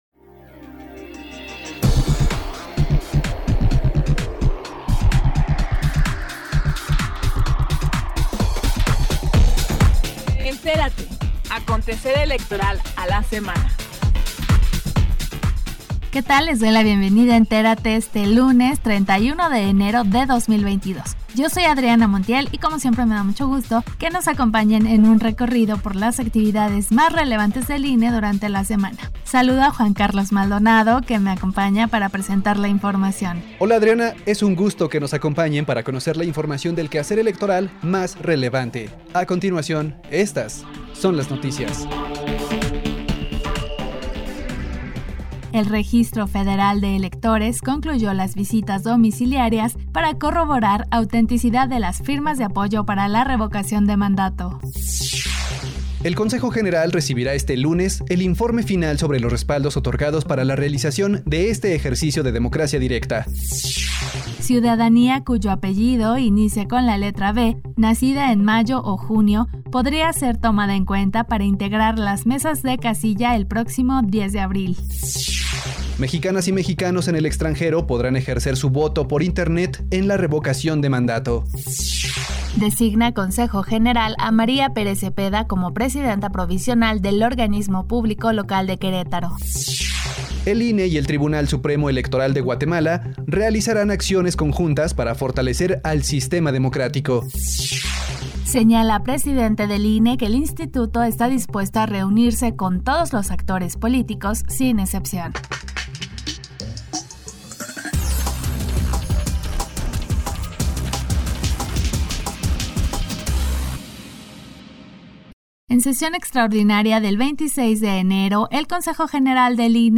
NOTICIARIO 31 ENERO 2022__1